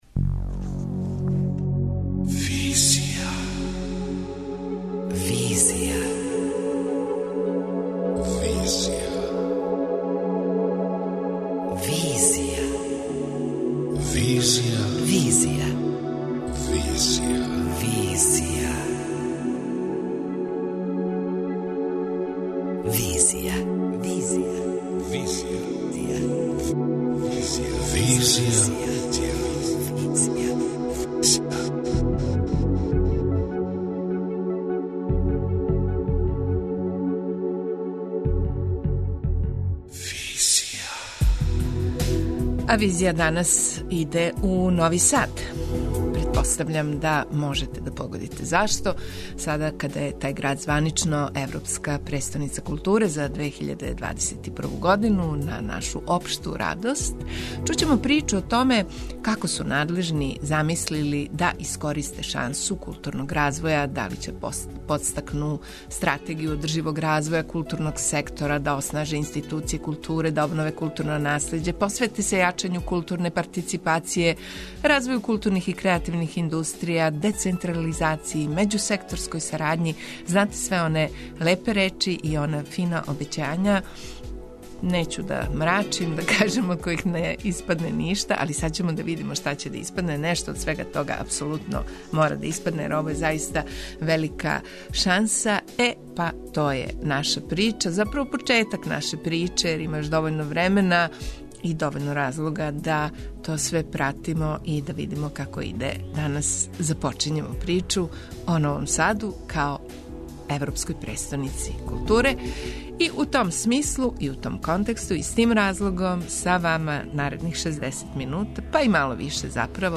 преузми : 27.40 MB Визија Autor: Београд 202 Социо-културолошки магазин, који прати савремене друштвене феномене.